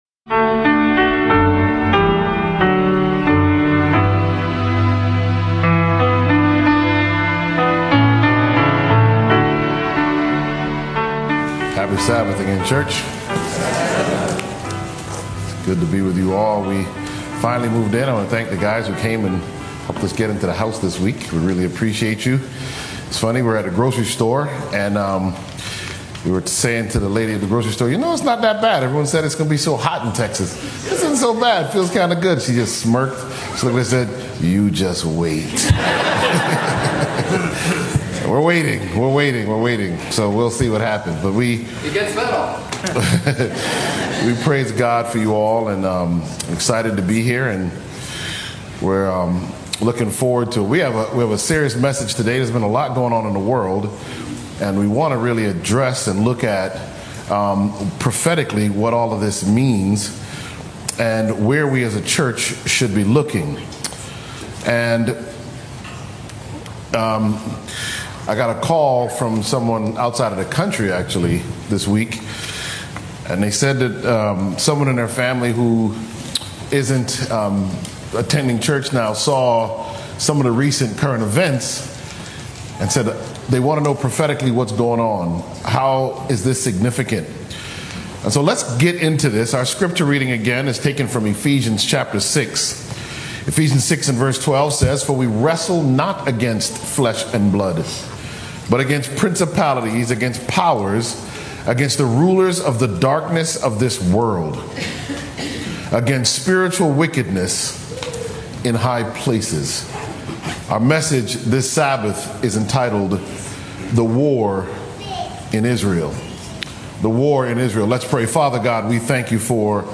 This sermon weaves together cutting-edge health science and deep spiritual truths, showing how early time-restricted eating benefits the body and how unwavering faith sustains the soul. From geopolitical conflict to end-time deception, it calls believers to spiritual vigilance, character transformation, and total reliance on the Holy Spirit.